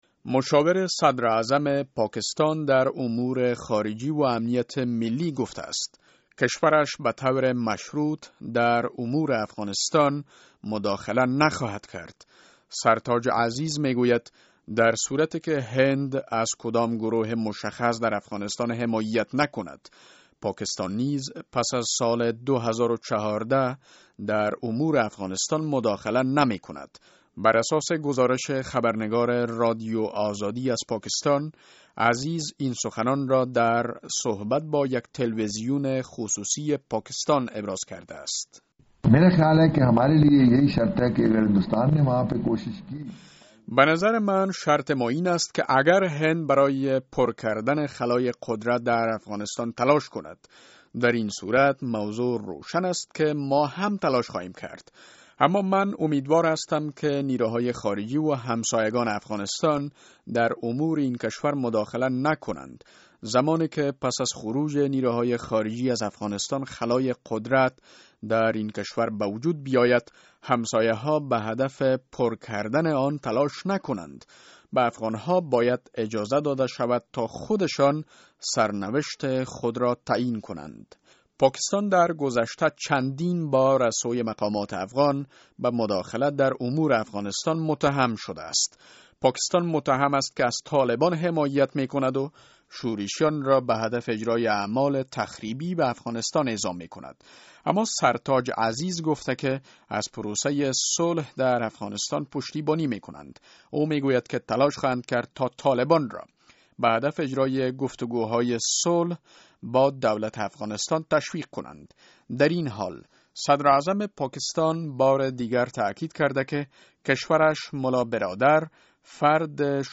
مصاحبه در مورد اظهارات اخیر مشاور صدراعظم پاکستان